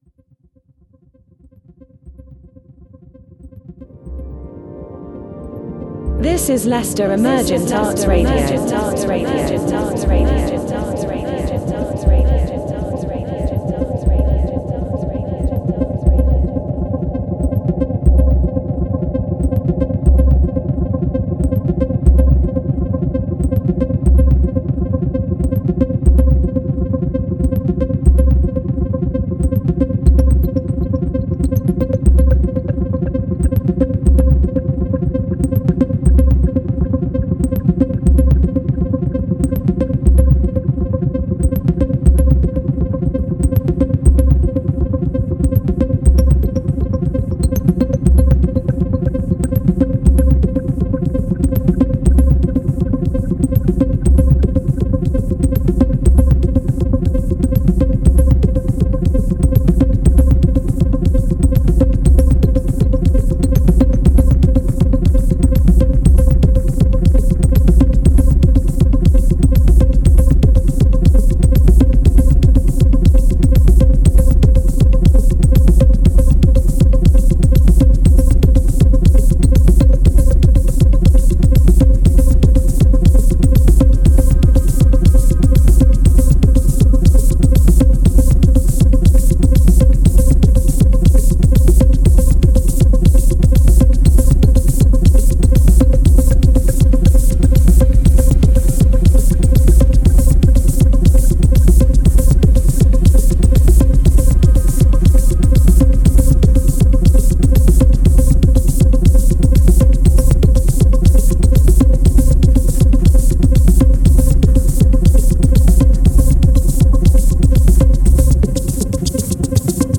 presents a celestial and introspective track